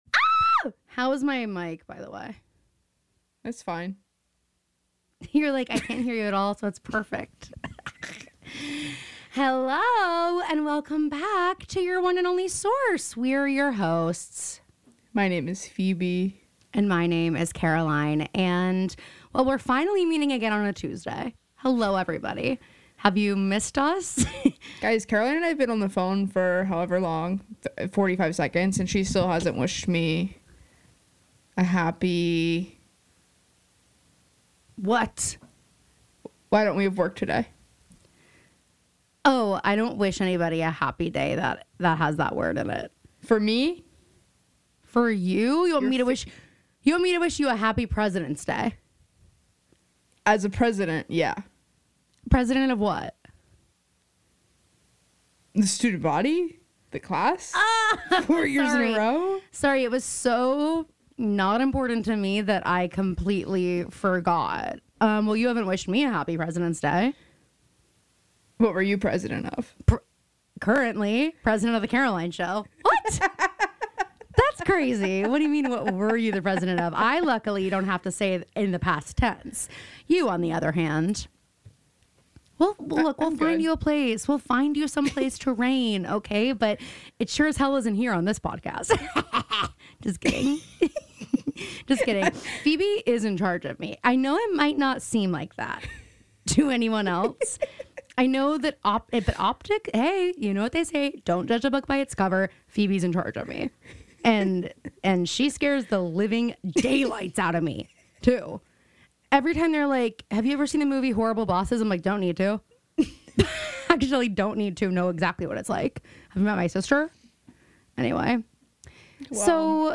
Add in quick takes on bold adaptation swings by Emerald Fennell with Wuthering Heights and the subtle craft choices that make prestige TV so addictive, and you’ve got a smart, chill, slightly scandalous listen for anyone who overthinks their binge-watch.